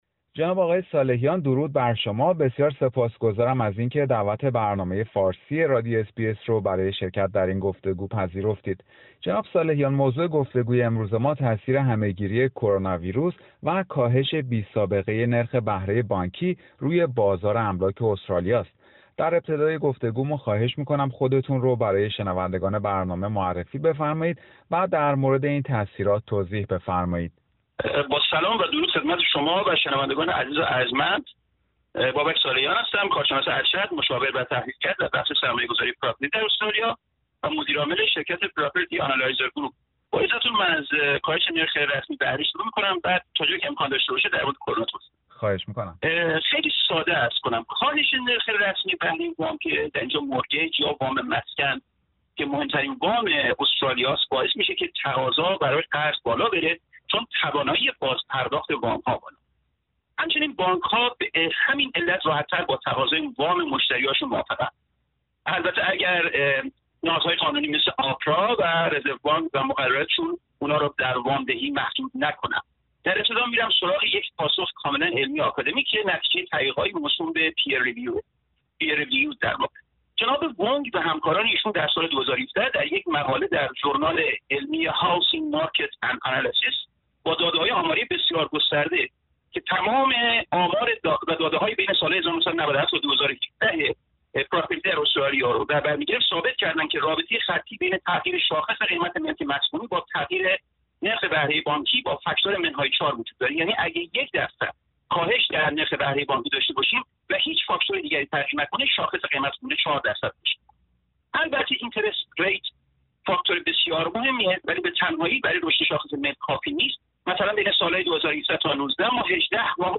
گفتگو با یک کارشناس املاک در مورد تاثیر همه گیری کووید-۱۹ روی بازار املاک استرالیا